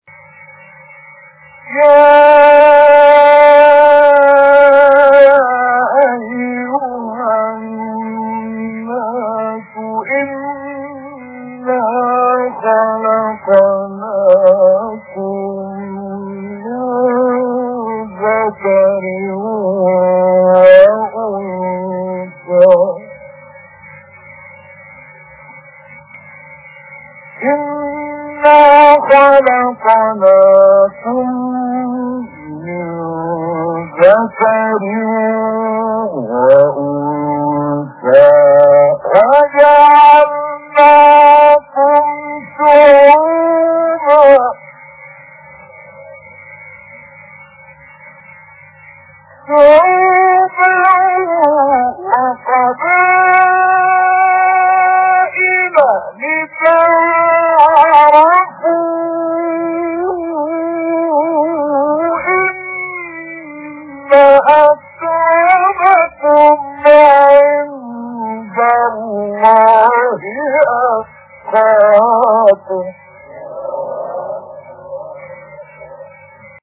۳ تلاوت کمیاب از استاد «عبدالفتاح شعشاعی» + دانلود/ تلاوتی با نفَس کوتاه
در ادامه سه قطعه از تلاوت‌های استاد شعشاعی ارائه می‌شود.